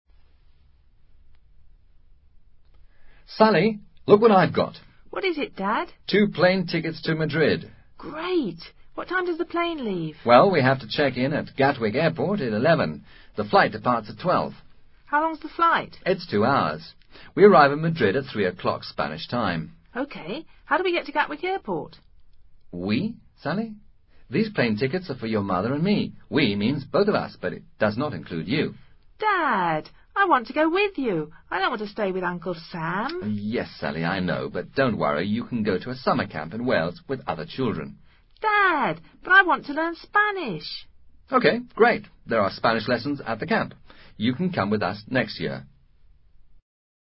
Diálogo entre un padre y su hija sobre sus próximas vacaciones.